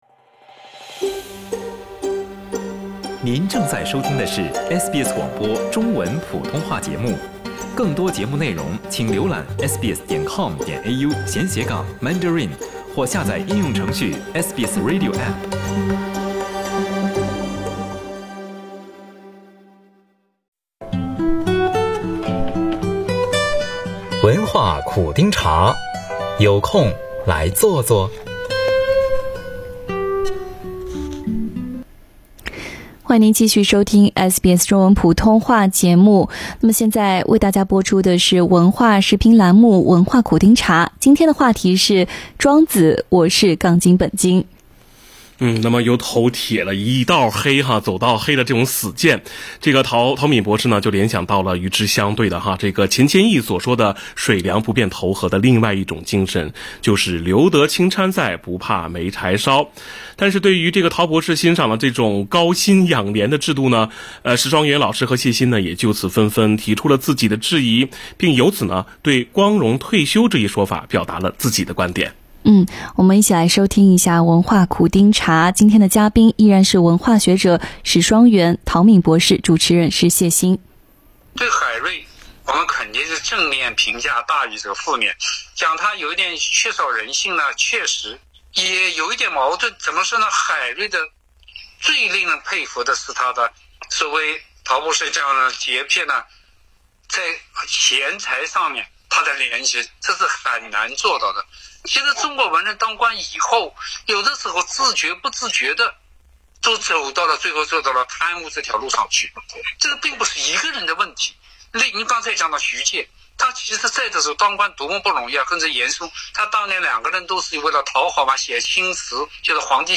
每周五播出的SBS 文化时评栏目《文化苦丁茶》，今天话题是：《庄子：我是杠精本精》。